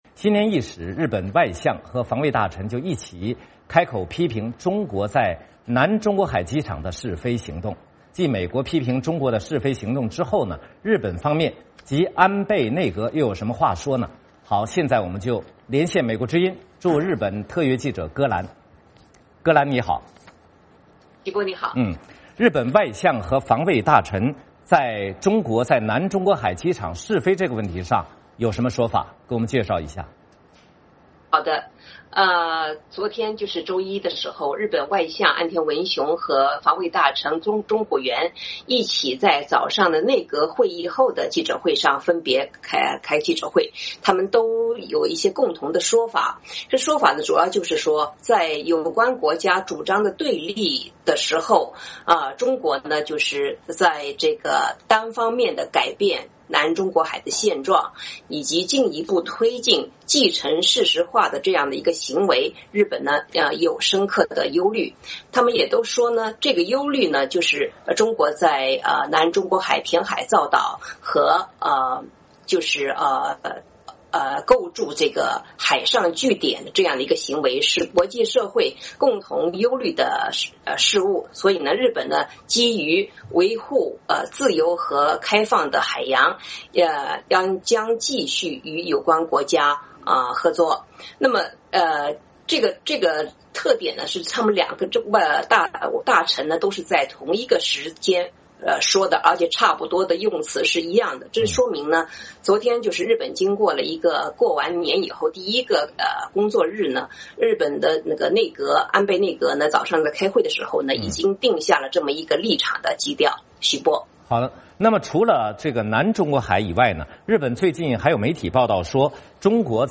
VOA连线：日本指责中国在南中国海机场试飞